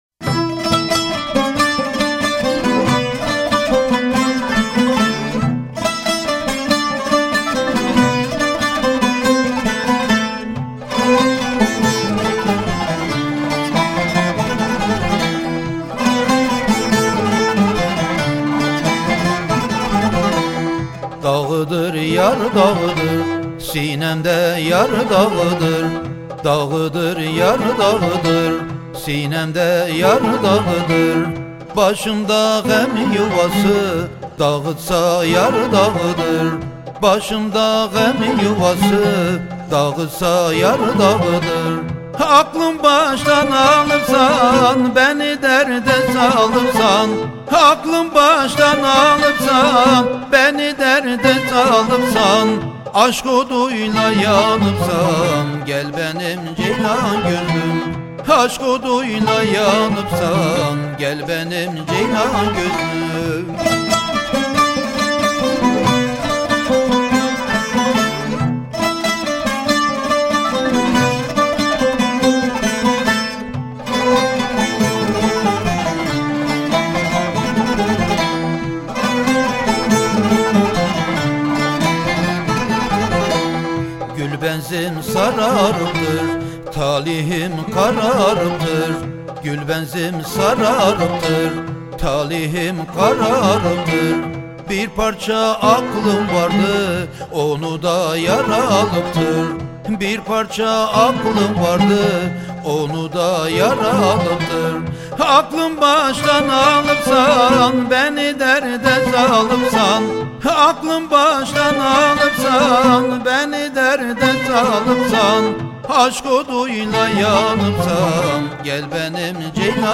Etiketler: urfa, türkü